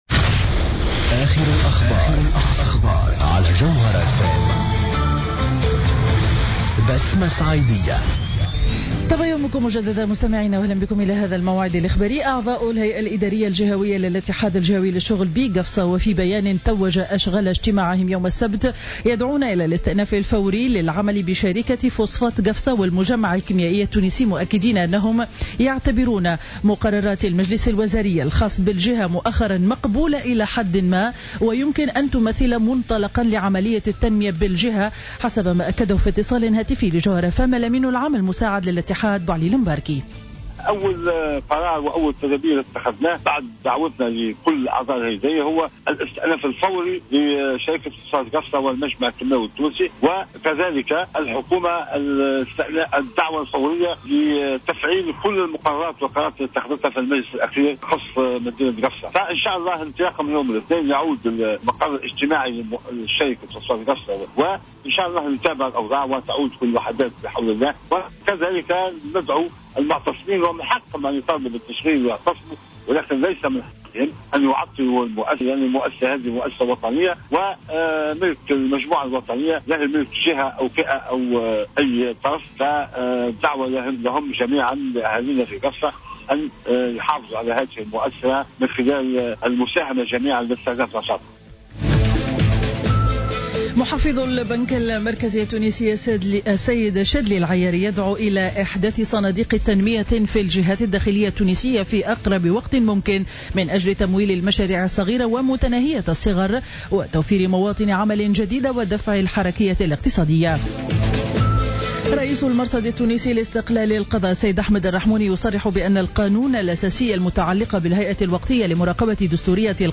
نشرة أخبار السابعة صباحا ليوم الأحد 24 ماي 2015